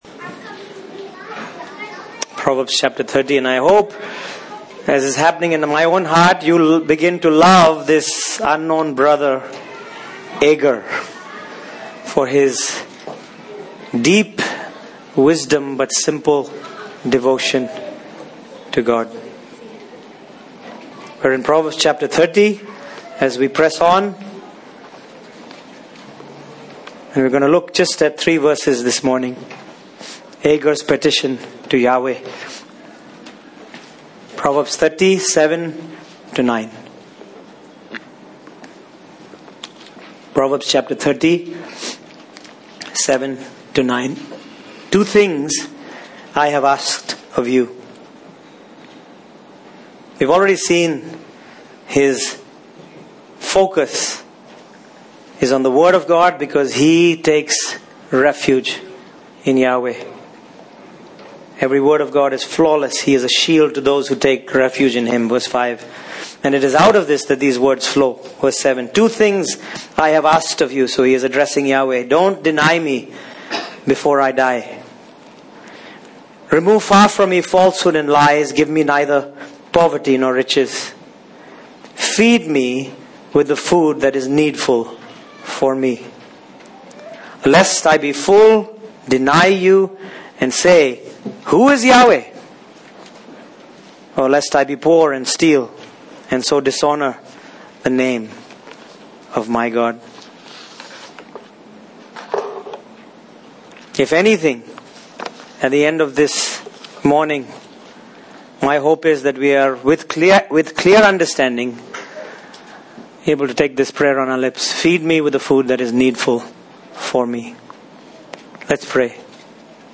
Proverbs 30:7-9 Service Type: Sunday Morning Topics: Contentment , Poverty , Wealth « Who is Wise?